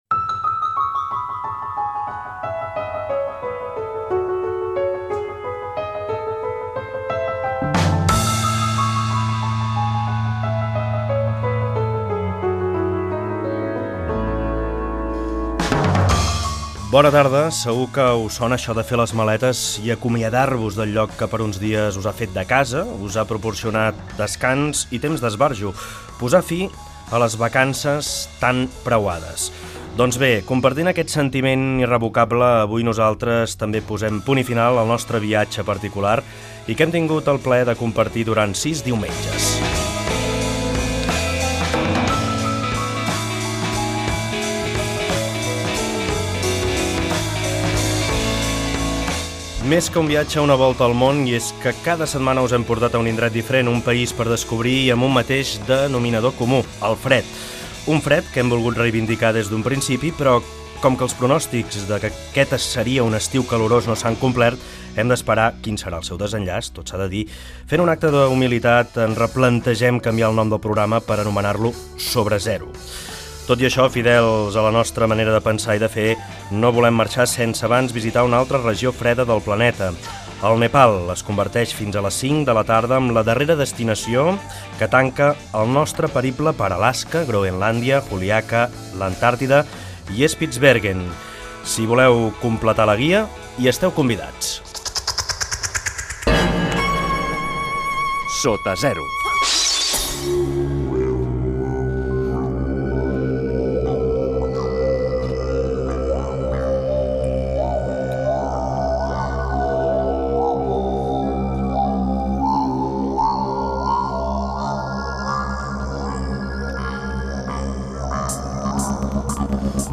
Sintonia i presentació de l'últim programa. Itineraris d'estiu per països on fa fred. En aquesta ocasió per Nepal.